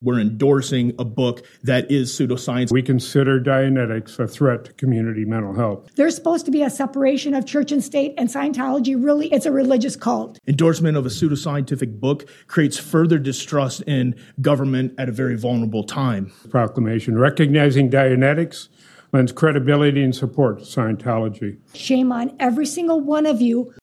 AUDIO: Scientology debate breaks out at Portage City Council meeting
Several residents, two of them mental health workers, called it a dangerous belief for the city to promote.